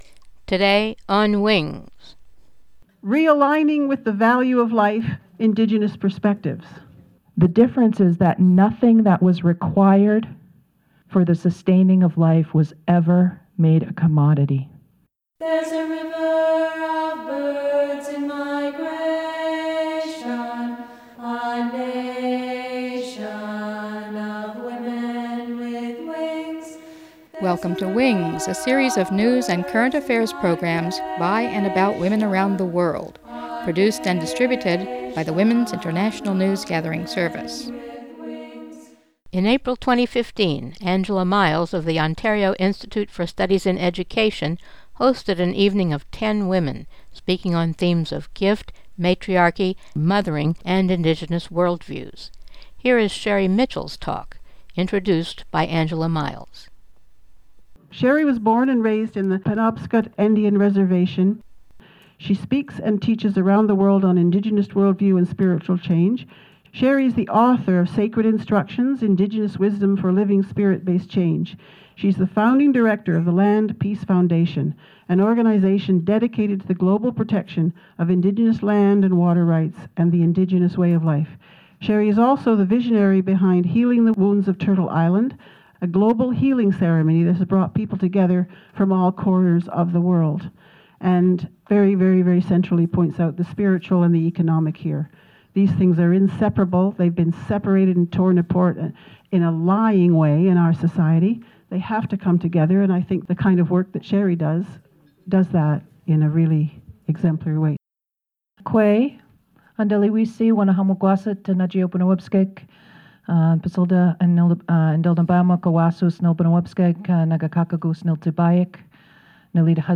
at OISE Toronto